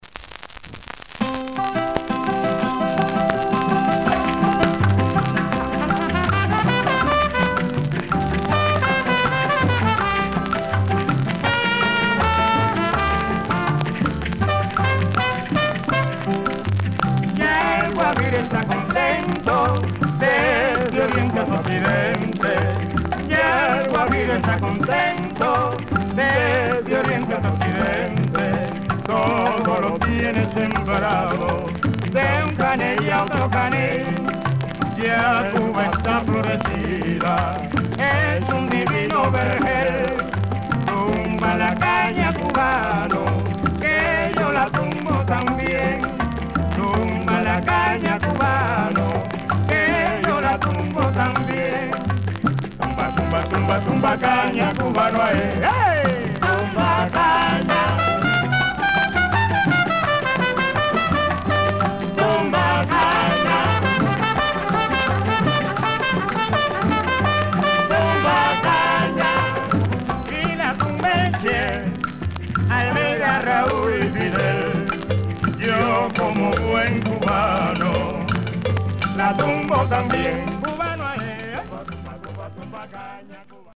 1920年代より活動する名門セプテット。